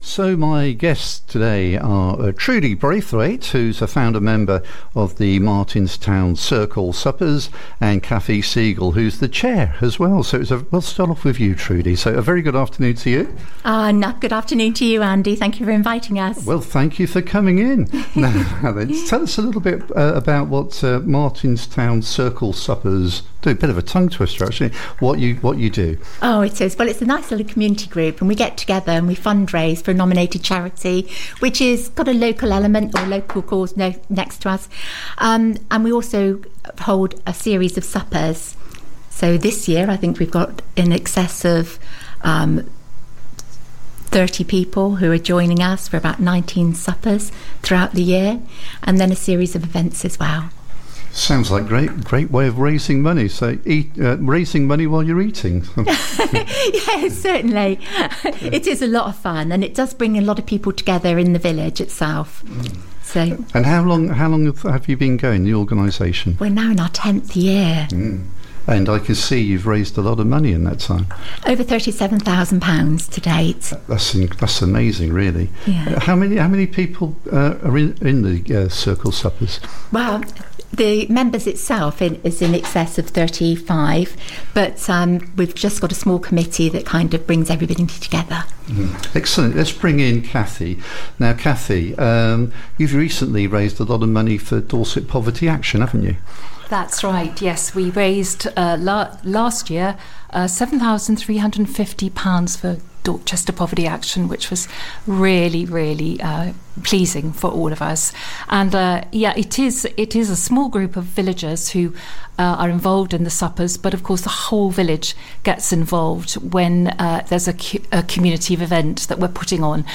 called in to the studio